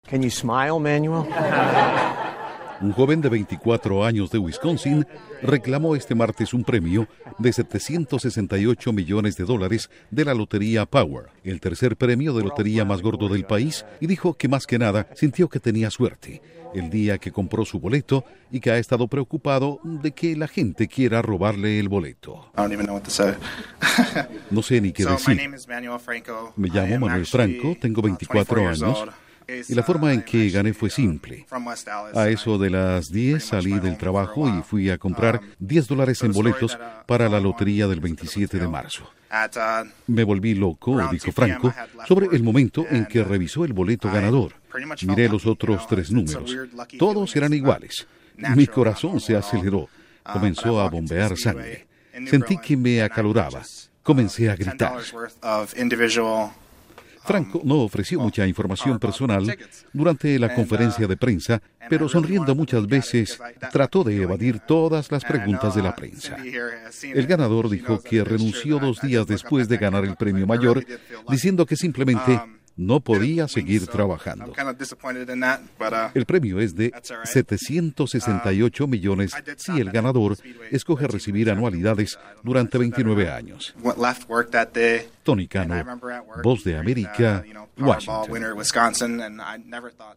Joven reclama premio de lotería de 768 millones de dólares en Wisconsin Informa desde la Voz de América en Washington